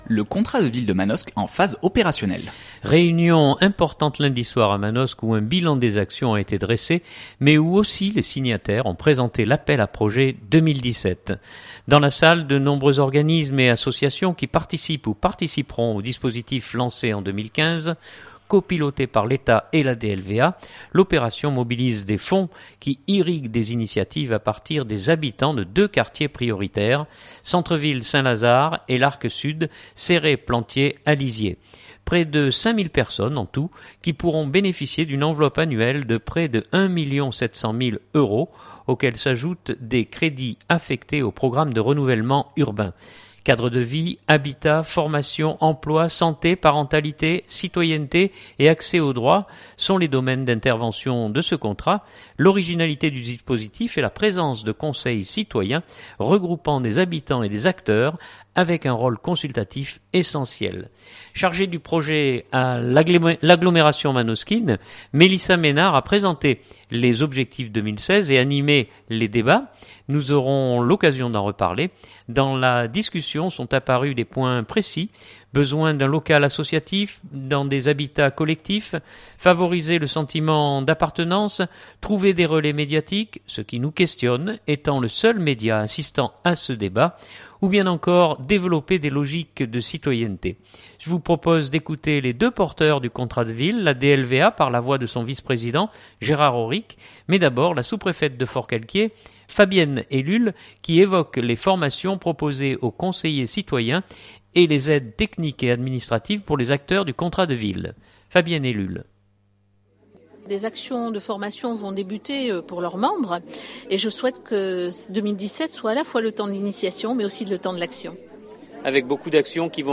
Je vous propose d’écouter les deux porteurs du contrat de ville : la DLVA par la voix de son vice-président Gérard Aurric, mais d’abord la sous-préfète de Forcalquier Fabienne Ellul qui évoque les formations proposées aux conseillers citoyens et les aides techniques et administratives pour les acteurs du contrat de ville. écouter : Durée : 4'45'' Journal du 2016-12-07 Contrat de ville.mp3 (3.23 Mo)